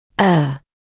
[ɜː][ёː]ошибаться, делать ошибку; заблуждаться; ошибка